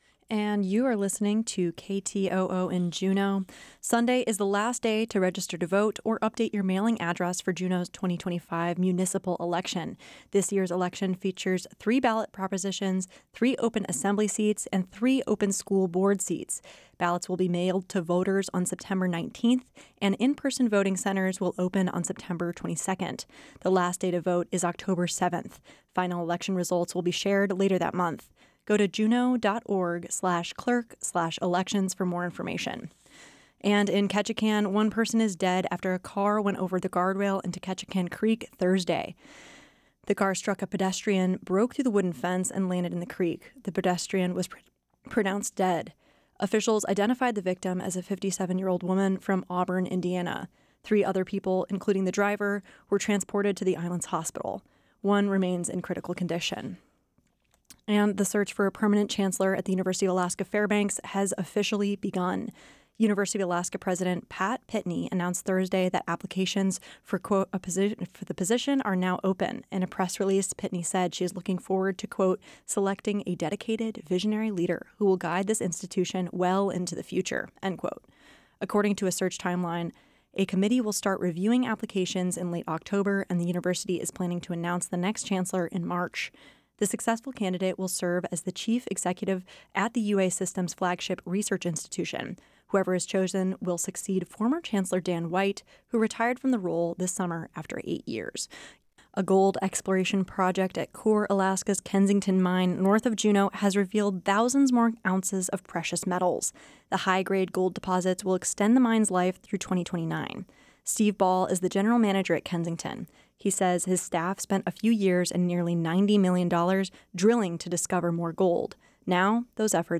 Newscast – Friday, Sept. 5, 2025 - Areyoupop